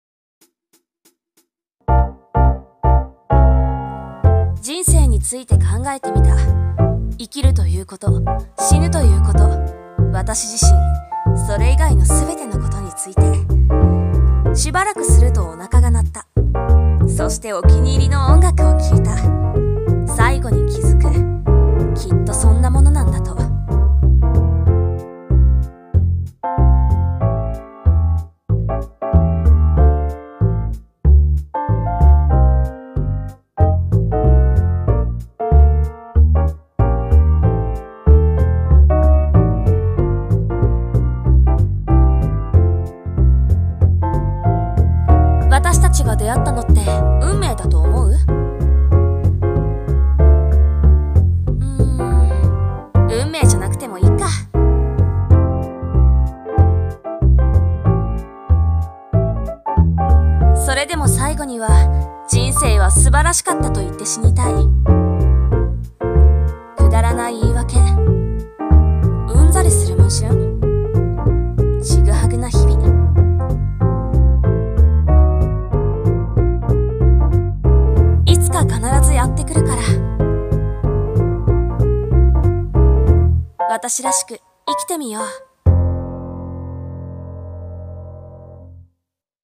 声劇】About Life